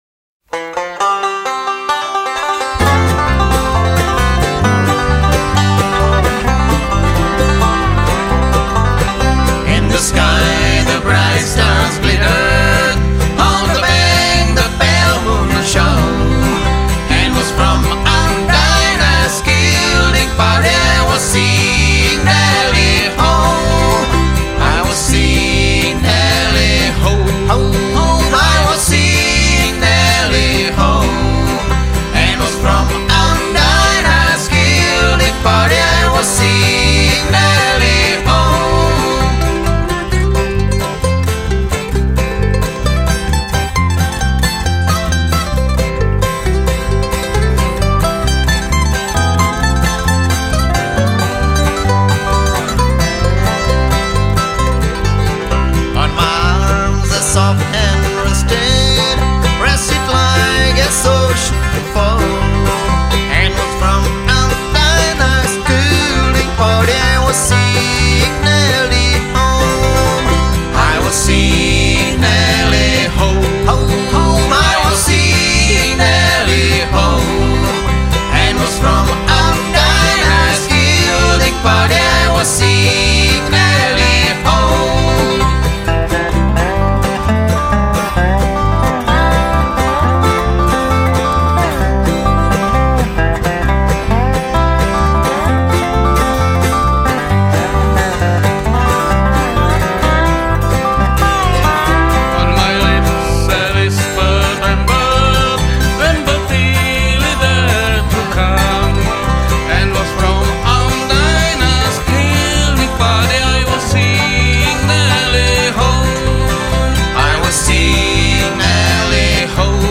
lead
baritone
tenor